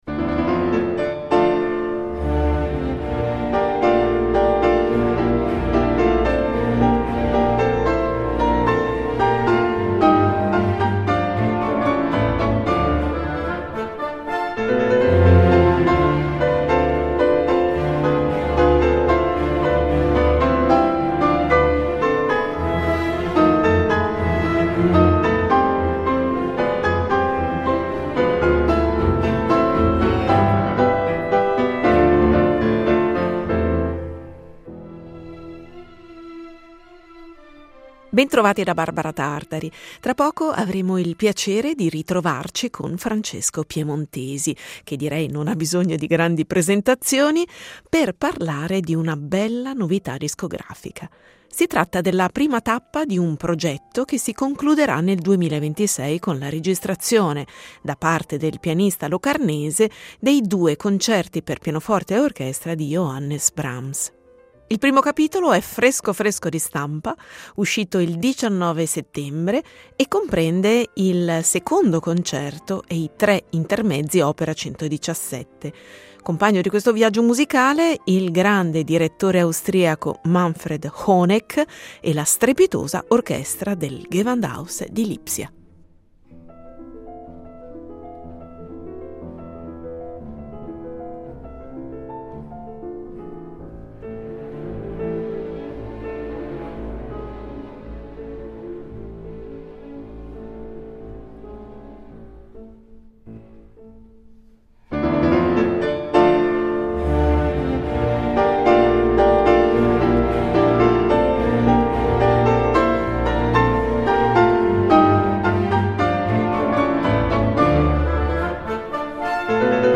ha incontrato Francesco Piemontesi al microfono di Rete Due